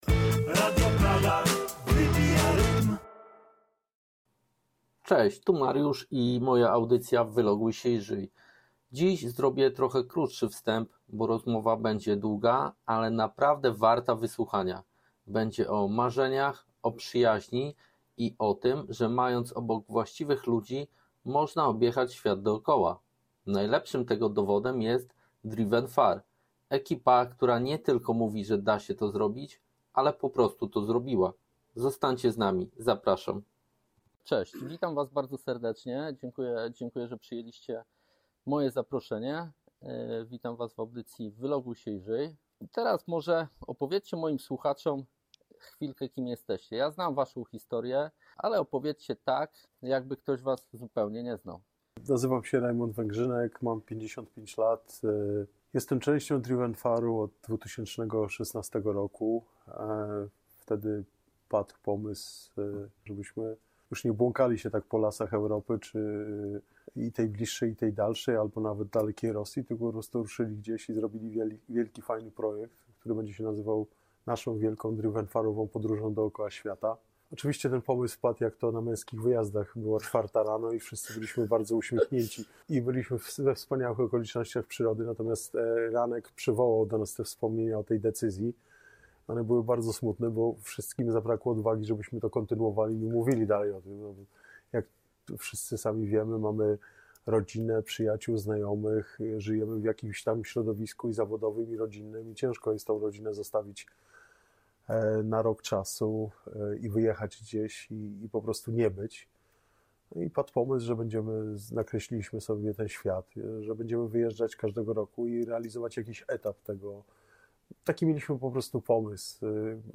To rozmowa, po której możesz złapać się na tym, że sam masz ochotę gdzieś ruszyć.